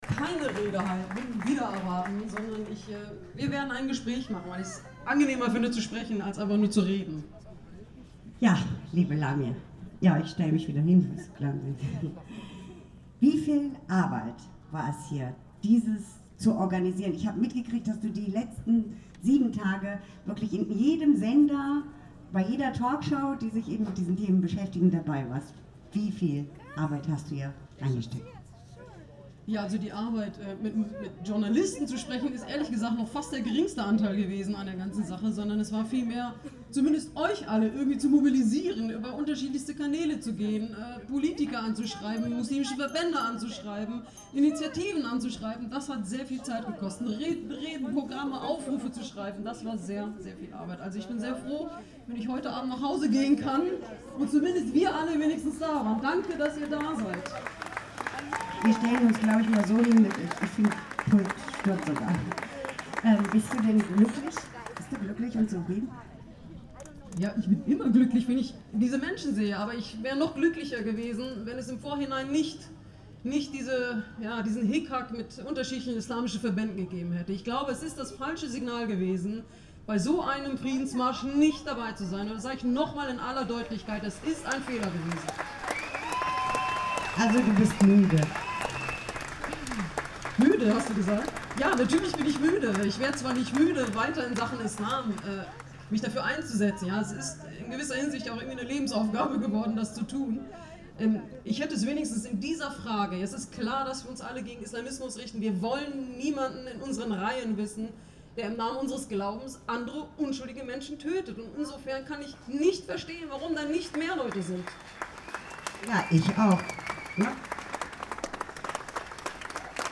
Gespräch mit Lamya Kaddor hier!